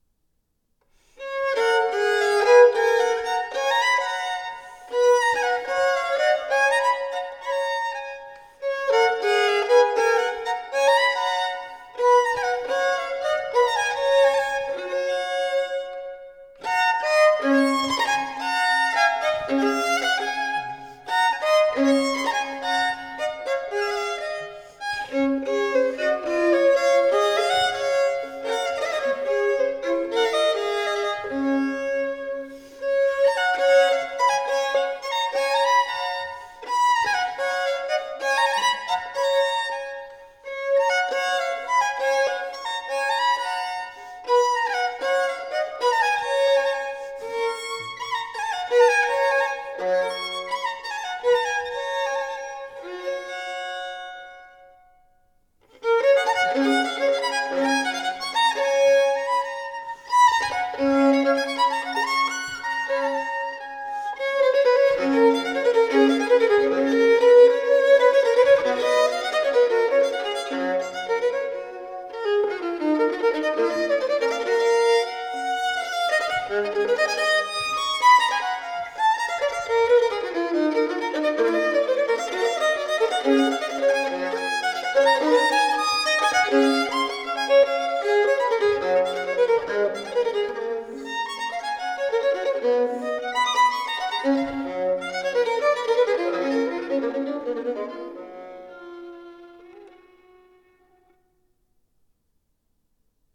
Sonate per violino solo